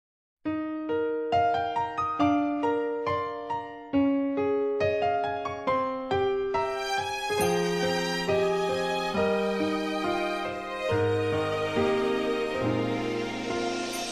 短信铃声